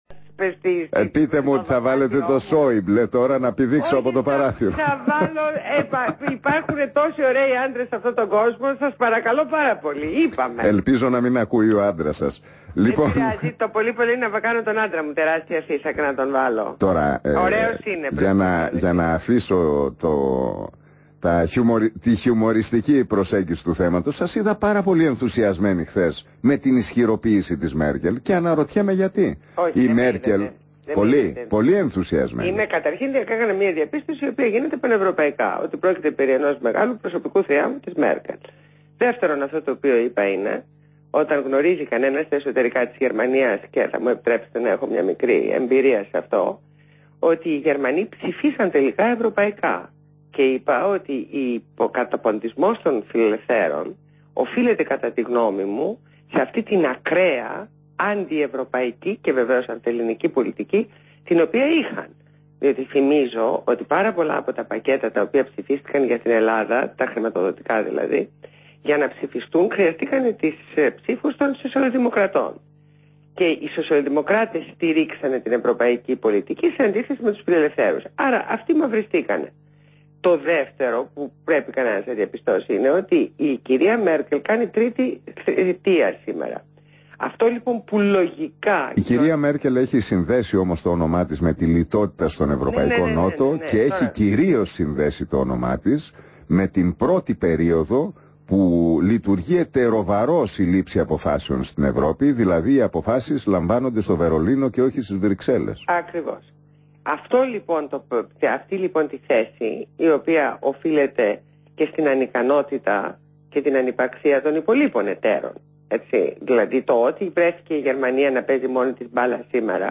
Συνέντευξη στον ραδιόφωνο του REAL
Ακούστε τη συνέντευξη που έδωσε η βουλευτής της ΝΔ Ντόρα Μπακογιάννη στο ραδιοφωνικό σταθμό RealFM 97,8 και τον δημοσιογράφο Νίκο Χατζηνικολάου.